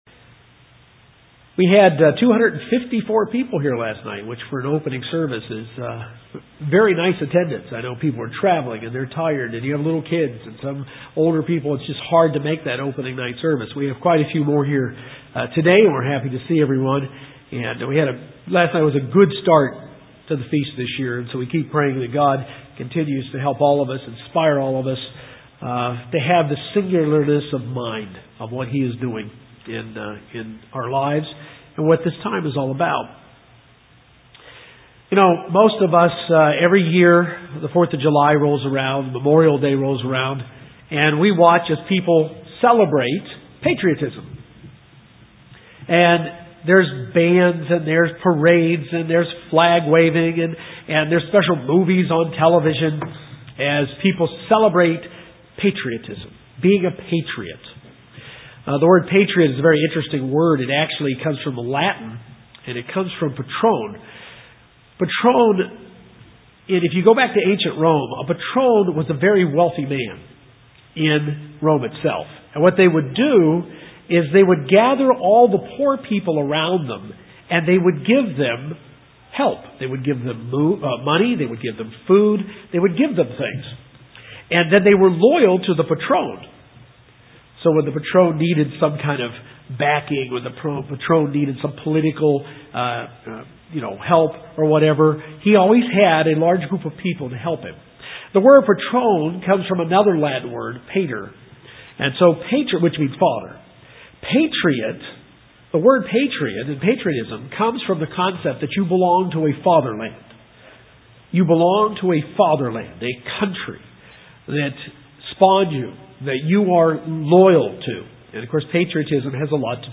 First day message FOT New Braunfels.